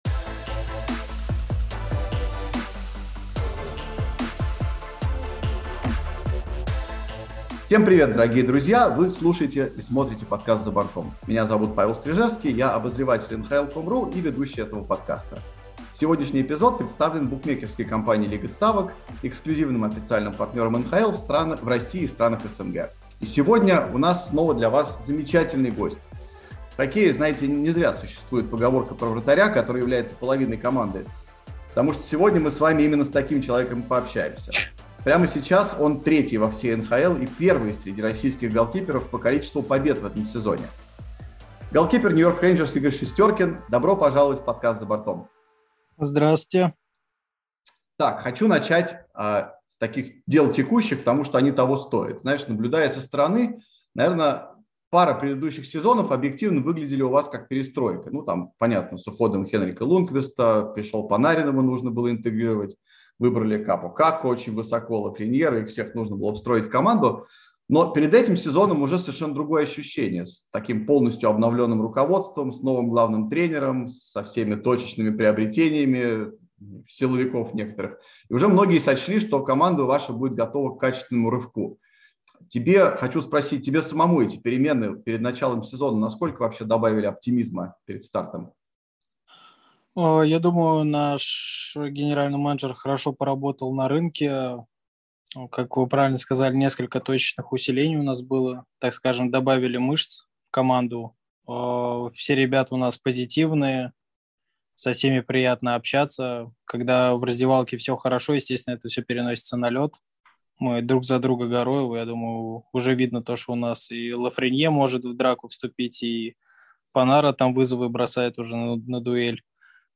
№30: интервью Игоря Шестеркина